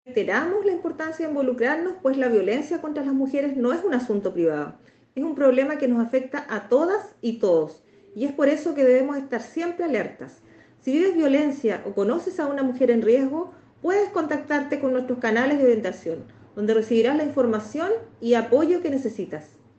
La directora regional (s) del SernamEG, Leyla Vilches Sánchez, precisó